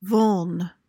PRONUNCIATION: (vuhln) MEANING: noun: Vulnerability: susceptibility to attack, injury, or temptation. verb tr.: To wound.